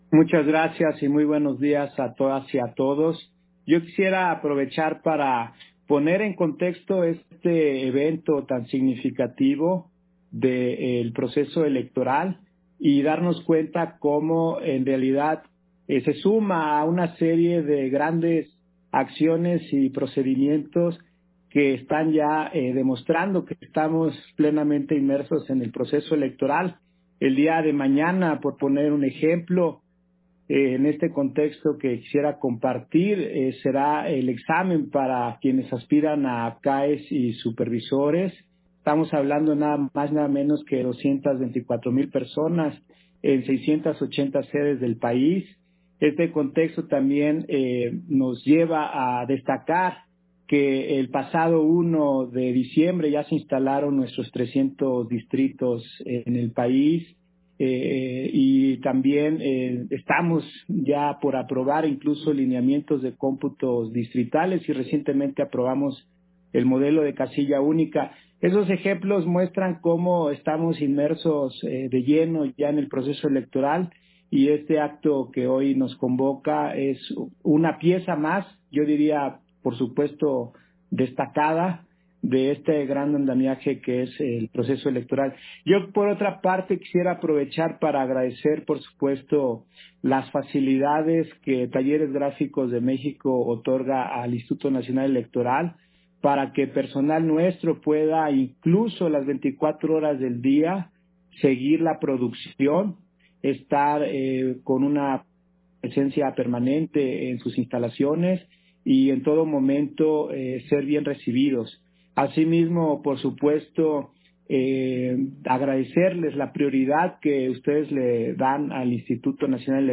Intervención de José Roberto Ruiz, en la firma de Colaboración Técnica INE-Talleres Gráficos de México